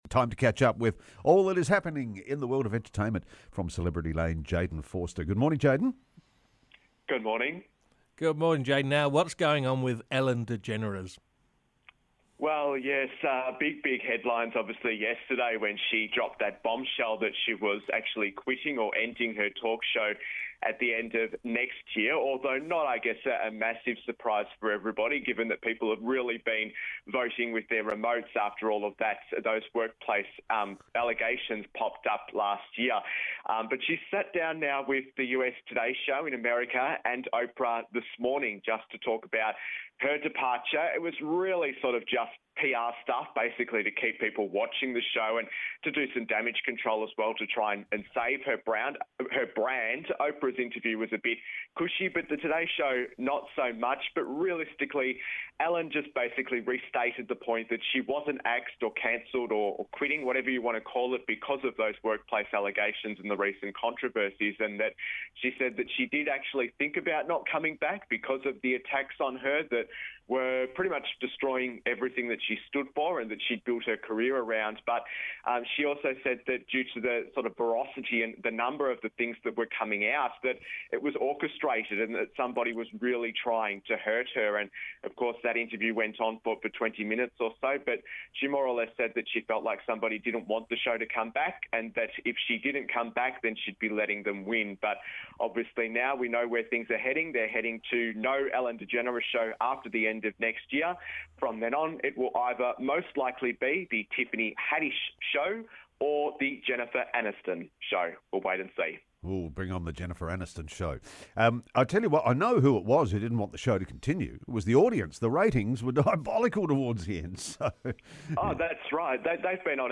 entertainment report